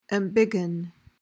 PRONUNCIATION: (em-BIG-uhn) MEANING: verb tr.: To make larger.